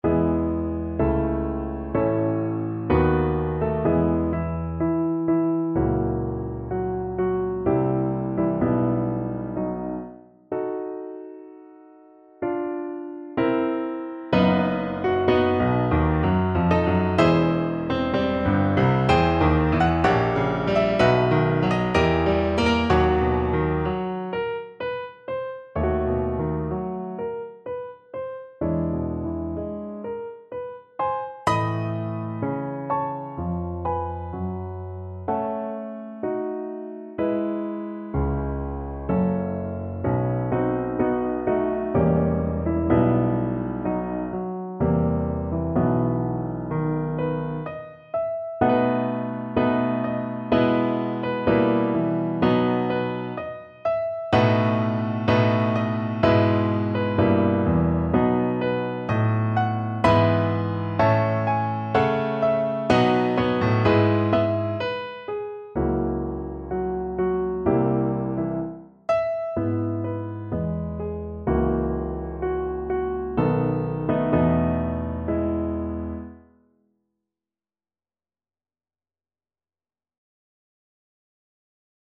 Piano version
No parts available for this pieces as it is for solo piano.
3/4 (View more 3/4 Music)
=63 Andante sostenuto
Piano  (View more Advanced Piano Music)
Classical (View more Classical Piano Music)